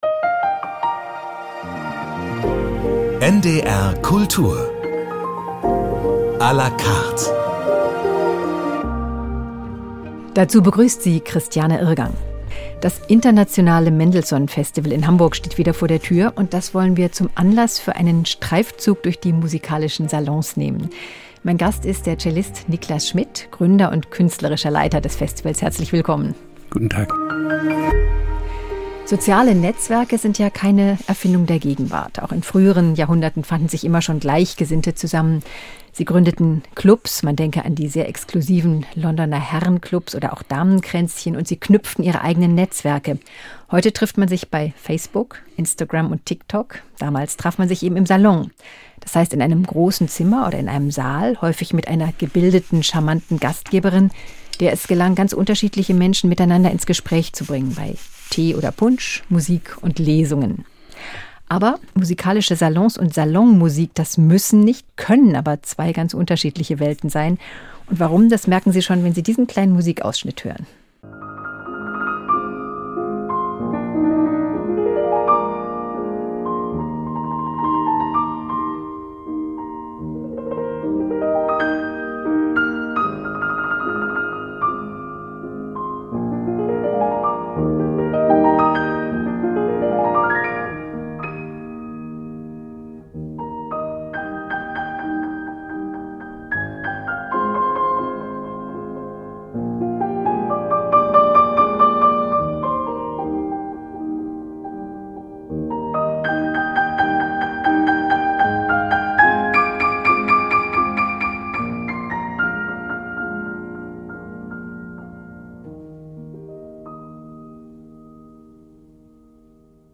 (00:00:00) Intro (01:10) Gebet einer Jungfrau (Auschnitt, Ende) (06:00) Streichquintett Nr. 1 A-Dur op. 18 - Scherzo - Allegro di molto (3. Satz) (16:56) Klavierkonzert Nr. 1 e-Moll, op. 11 - Larghetto (2. Satz), Ausschnit (26:06) Fünf kleine Stücke für Klavier, S 192 - Lento assai (Nr. 2 As-Dur) (33:17) Impromptu für Harfe, op. 86 (40:43) Trio F-Dur für Flöte, Fagott und Klavier - Larghetto (1. Satz) (48:40) Präludium Nr. 1 C-Dur für Violoncello und Klavier Mehr